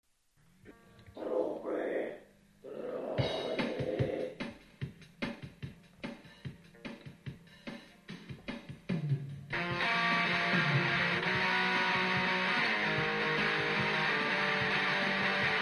Запись крайне трушная...
keyboard, percussions, vocal, backvocal.
guitar, vocal, backvocal.